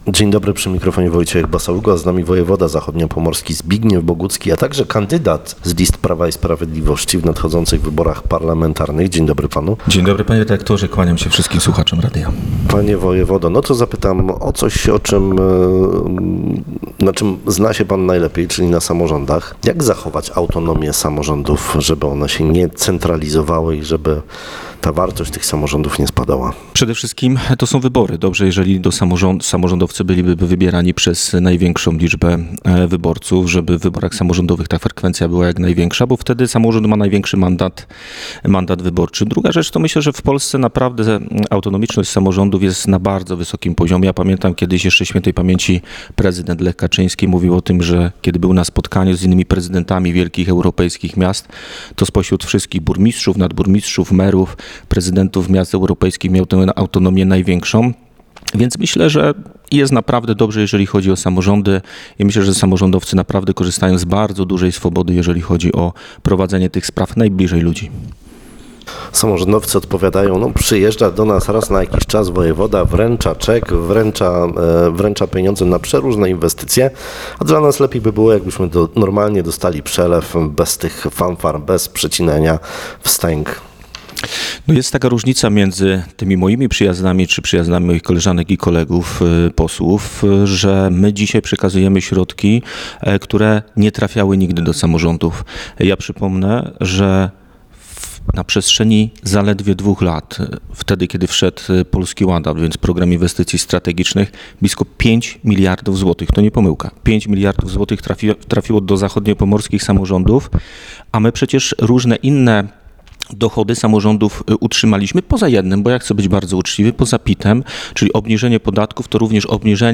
Wojewoda Zachodniopomorski Zbigniew Bogucki był w czwartek Gościem Rozmowy Dnia. Polityk mówił pracy dla zachodniopomorskiego samorządu, inwestycjach przeprowadzonych przez rząd Prawa i Sprawiedliwości, a także tych planowanych i na ukończeniu. Zapytany o podział mandatów w okręgu z którego startuje do sejmu, odpowiedział, że planem minimum jest utrzymanie 4 mandatów, jednak jego ugrupowanie będzie „biło się” o piąty mandat.